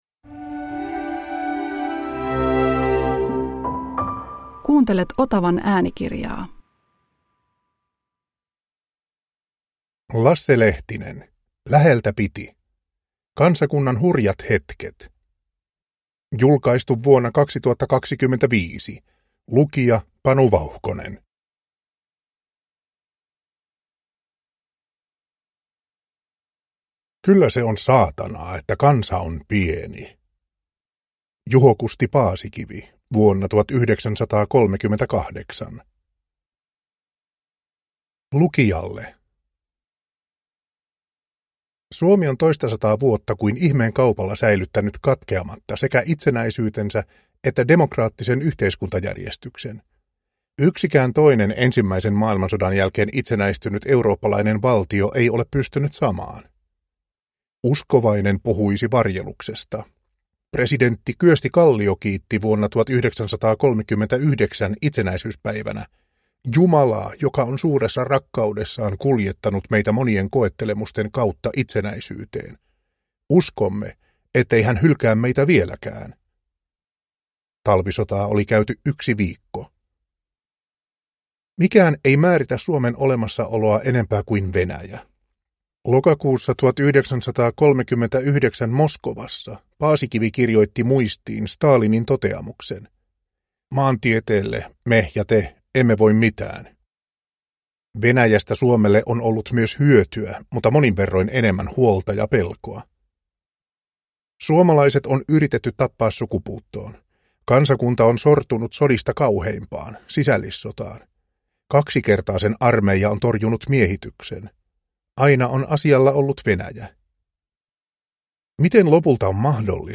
Läheltä piti – Ljudbok